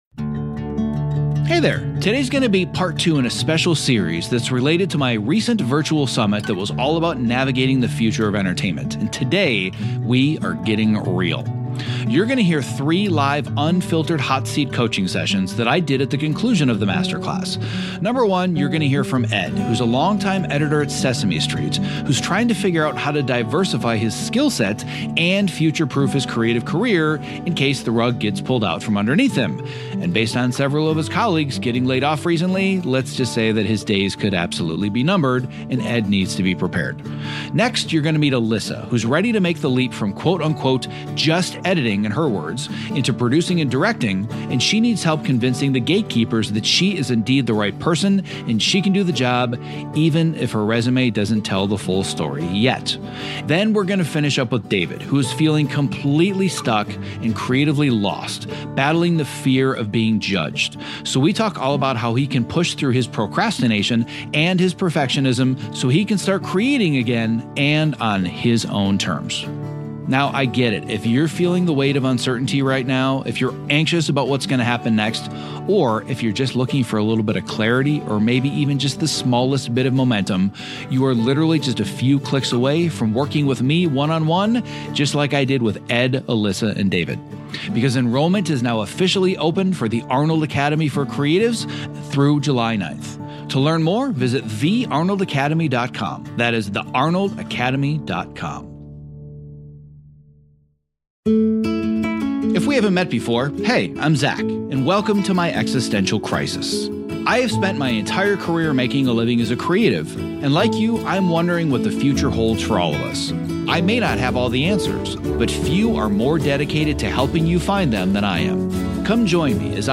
What would happen if you finally got the clarity and coaching you needed to take the next step in your creative career? In today’s episode, part two of the live masterclass from my recent virtual summit, I’m sharing raw, unfiltered Hot Seat coaching sessions with three creatives facing different forms of uncertainty.